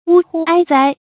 發音讀音
成語簡拼 whaz 成語注音 ㄨ ㄏㄨ ㄞ ㄗㄞ 成語拼音 wū hū āi zāi 發音讀音 常用程度 常用成語 感情色彩 中性成語 成語用法 復雜式；作謂語；含諷刺意味 成語結構 復雜式成語 產生年代 古代成語 成語正音 哉，不能讀作“zhāi”。